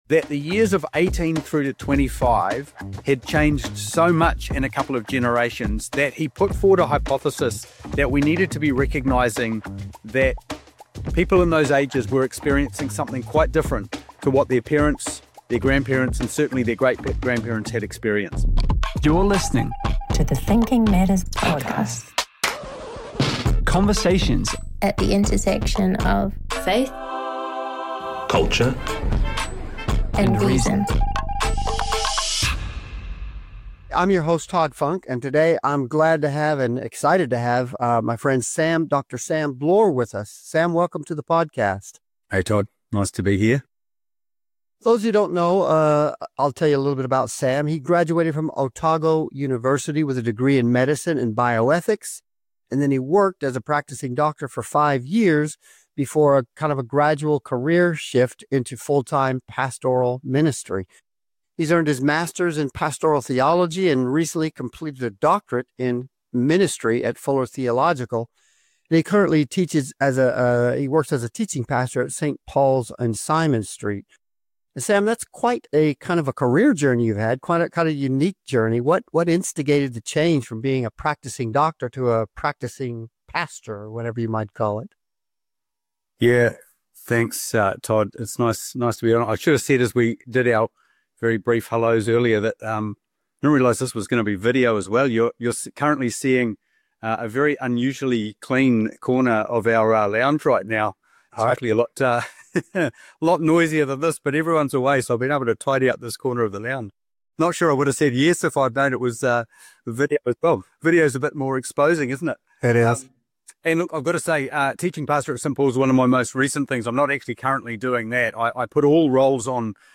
The conversation emphasizes the importance of community, support, and spiritual practices in navigating the challenges of emerging adulthood.